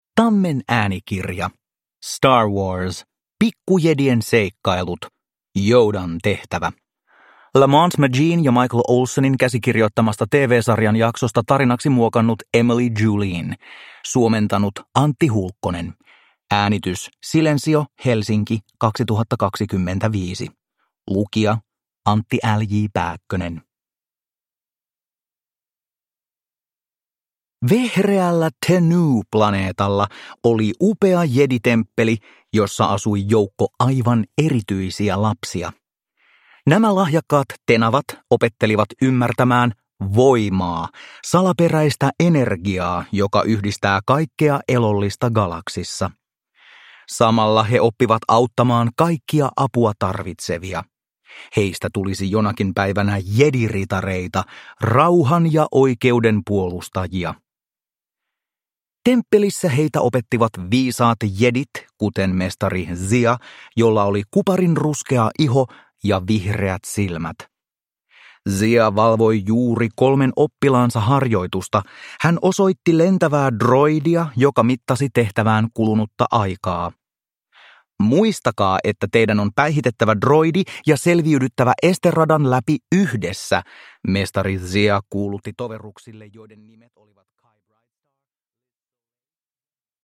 Star Wars. Pikkujedien seikkailut. Yodan tehtävä – Ljudbok